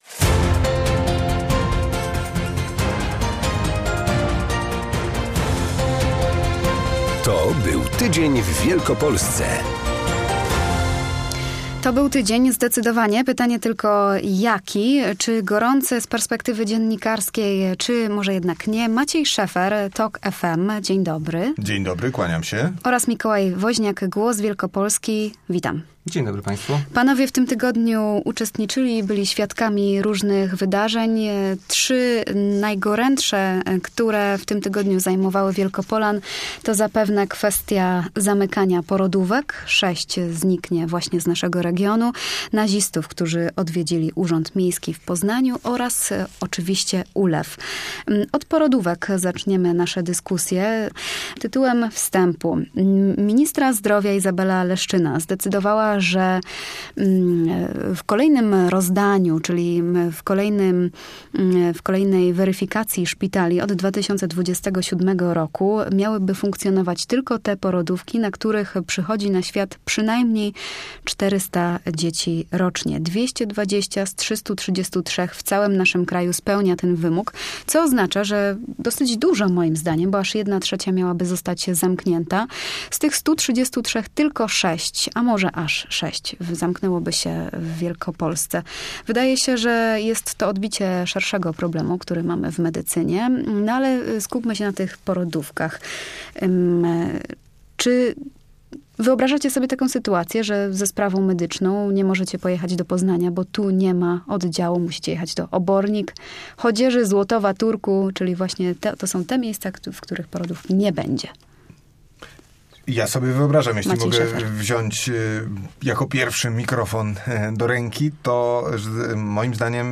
Trzy najważniejsze tematy tego tygodnia w Wielkopolsce - zamykanie porodówek, ulewy oraz naziści, którzy odwiedzili Urząd Miasta w Poznaniu. O swoich wnioskach i spostrzeżeniach opowiadają lokalni dziennikarze.